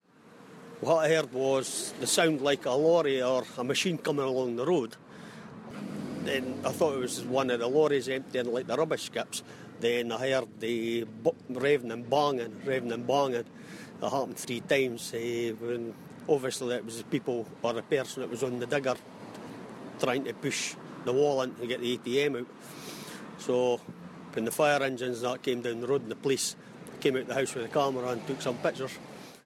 A neighbour describes the moment a man tried to steal a cash machine from the Co-op on Lauder Road, Kirkcaldy.